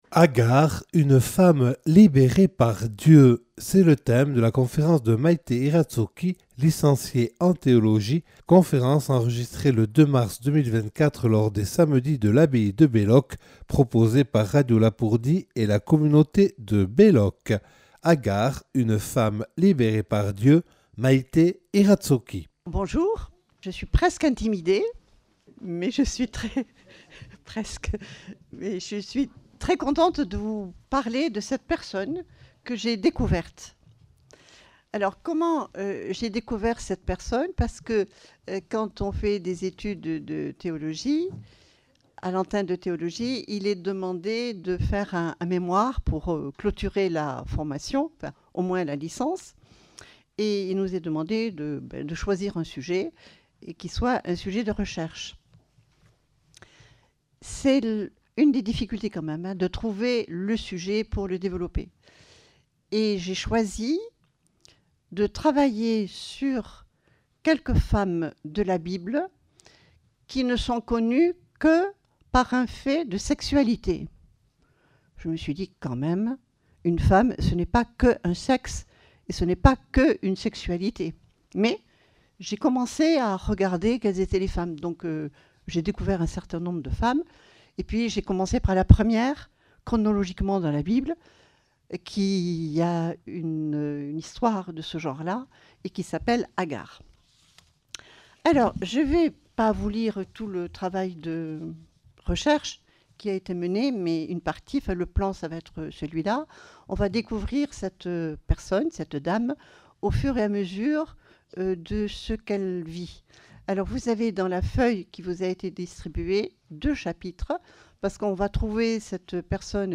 Une conférence
(Enregistré le 02/03/2024 lors des Samedis de l’Abbaye de Belloc proposés par Radio Lapurdi et la communauté de Belloc).